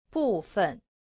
• bùfen